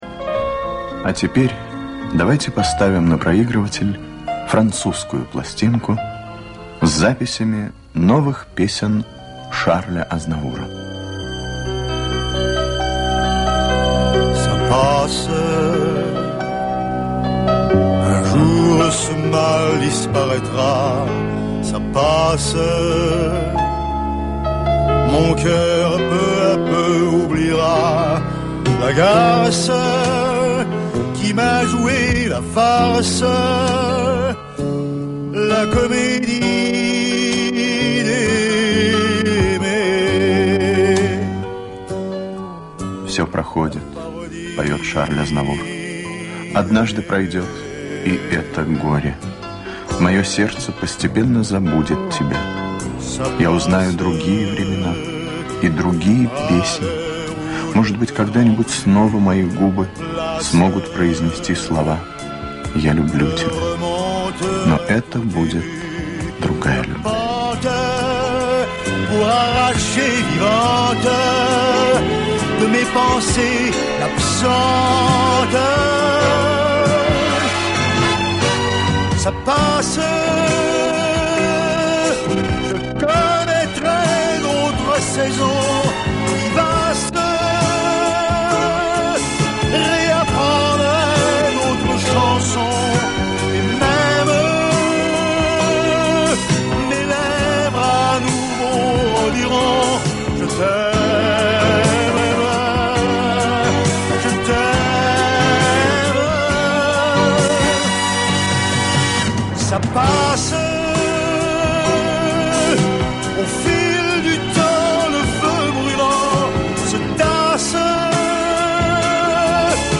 Первая программа Всесоюзного радио. "Музыкальный глобус" (рассказ о Шарле Азнавуре, вед. Виктор Татарский). Оцифровка старой ленты.